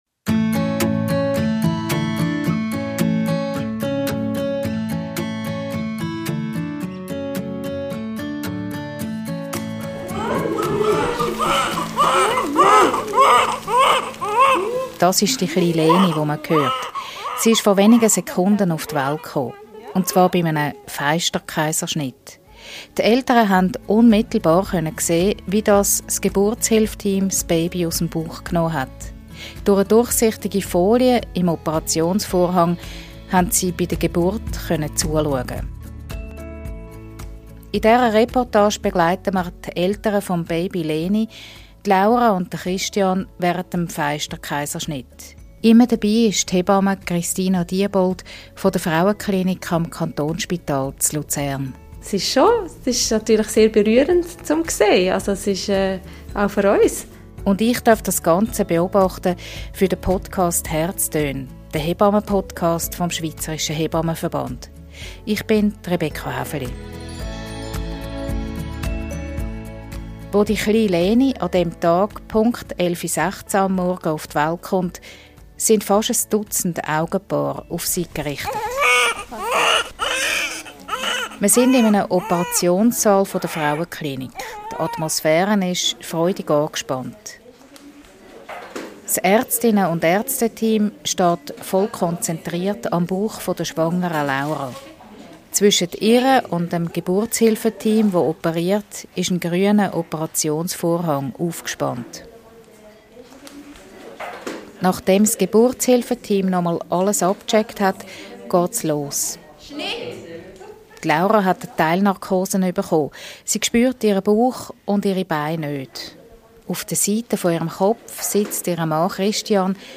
Reportage von einem Fenster-Kaiserschnitt